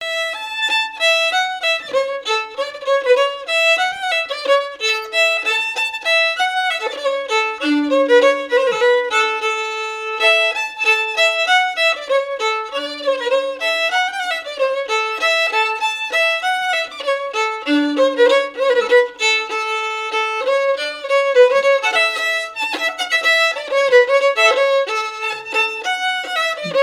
danse : scottich trois pas
violoneux
Pièce musicale inédite